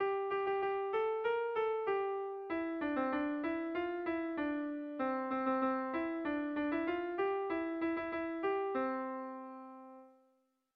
Melodías de bertsos - Ver ficha   Más información sobre esta sección
Irrizkoa
8A / 8B / 10A / 8B
ABD